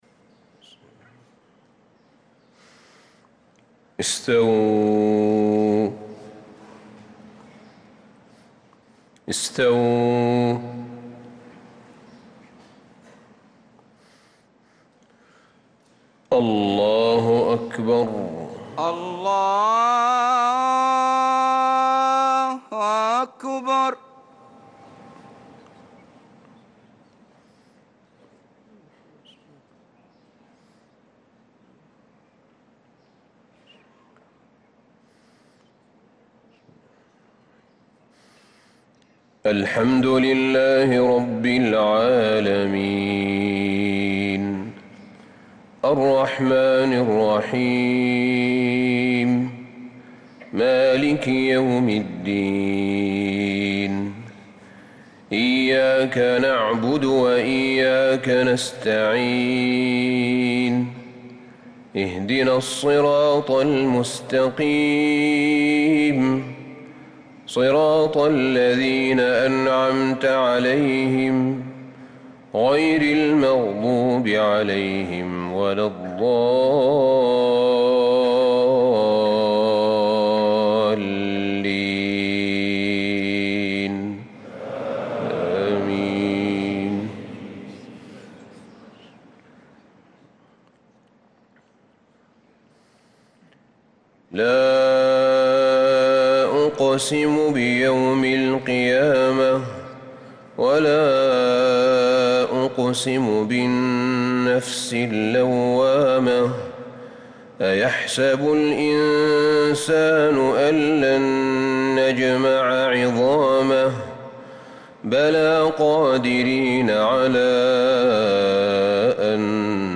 صلاة الفجر للقارئ أحمد بن طالب حميد 25 محرم 1446 هـ